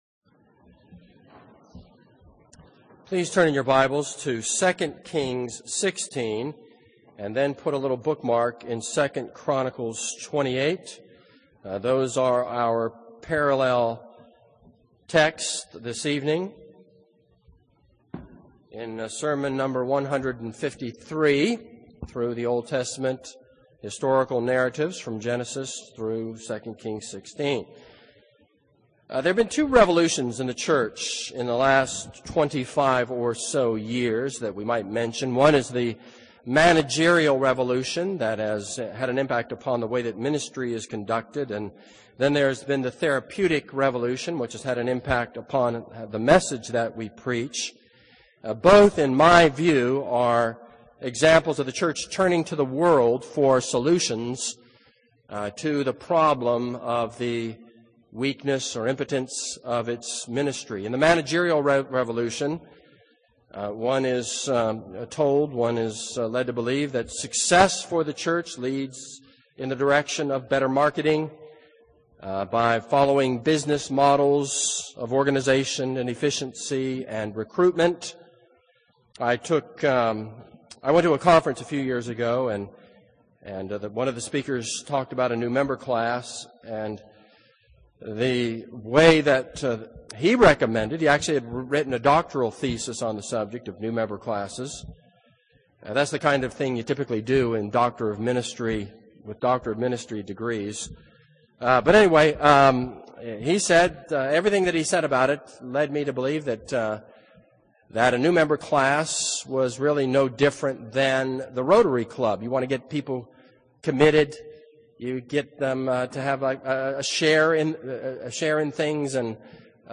This is a sermon on 2 Kings 16.